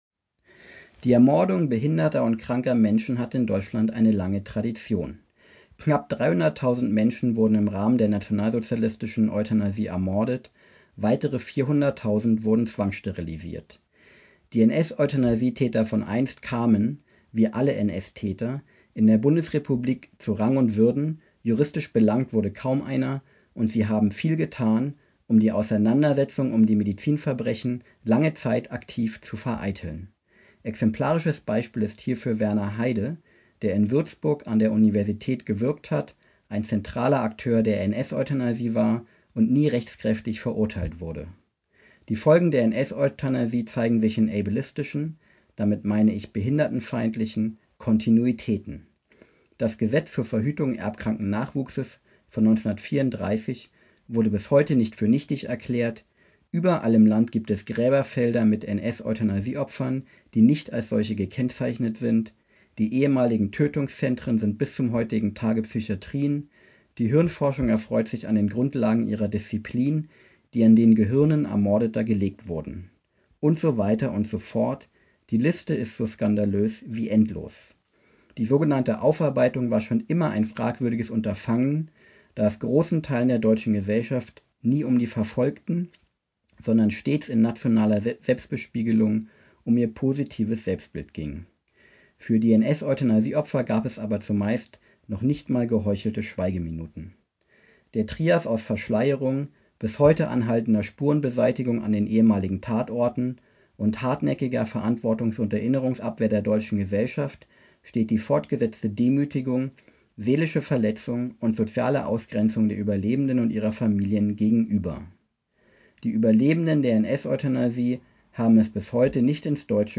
04/2025: „Ableismus tötet! Die Ermordeten von Potsdam nicht vergessen“. Gedenkrede anlässlich des 4. Jahrestags der Morde in Potsdam am 28.04.2025 in Würzburg.